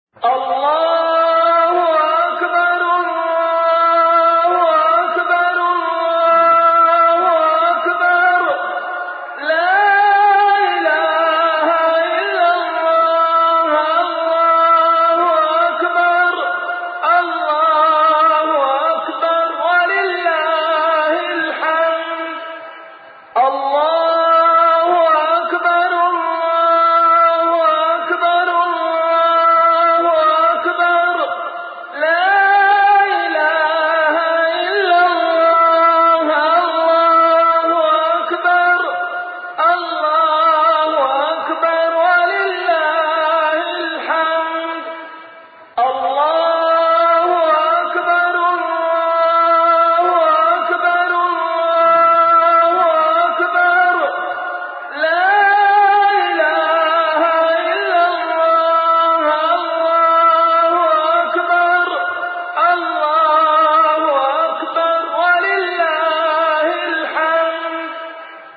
أناشيد ونغمات
عنوان المادة تكبير العيد